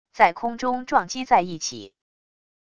在空中撞击在一起wav音频